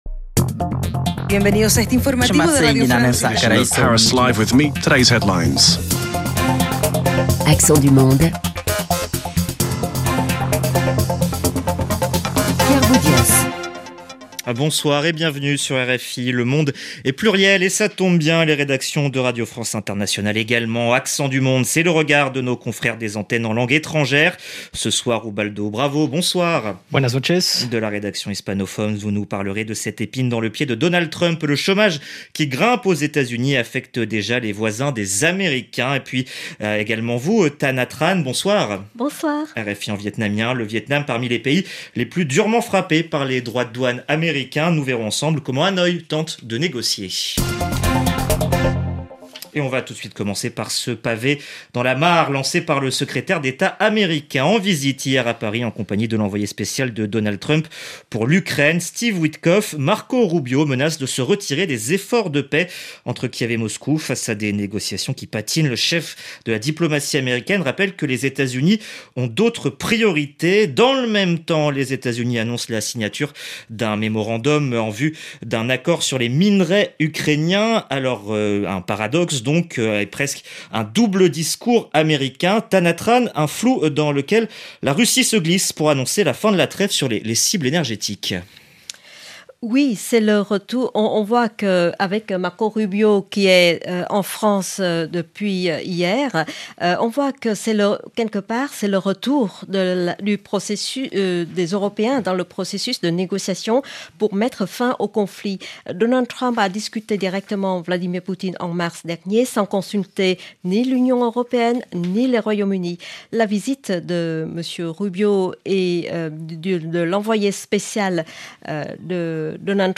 Le vendredi soir, dans Accents du monde, les journalistes des rédactions en langues étrangères croisent leurs regards, en français, sur l’actualité internationale et partagent les événements et les faits de société de leur région.